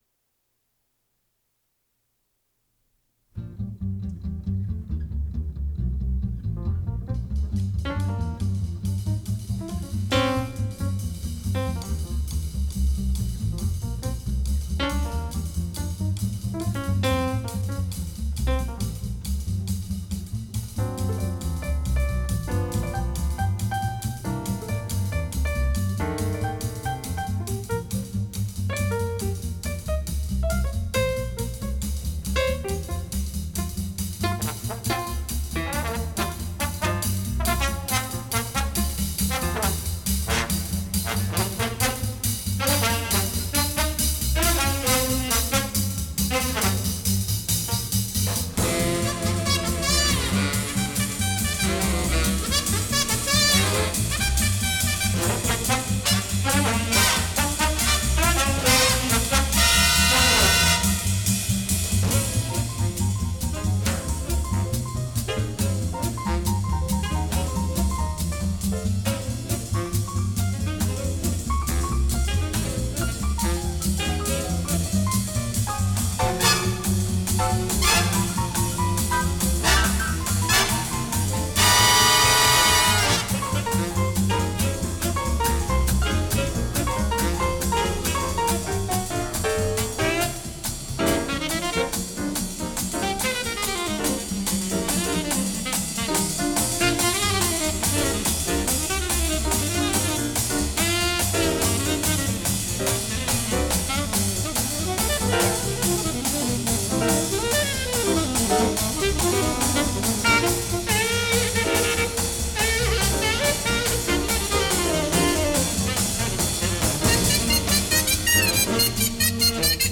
two orchestras
swing